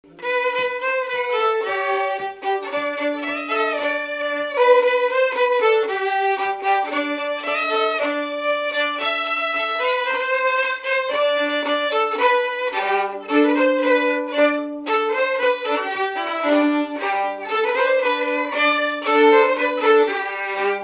Fonction d'après l'analyste gestuel : à marcher
Pièce musicale inédite